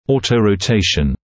[ˌɔːtəurəu’teɪʃn][ˌоːтоуроу’тэйшн]ауторотация (передняя ротация нижней челюсти как часть естественного процесса или как результат лечения)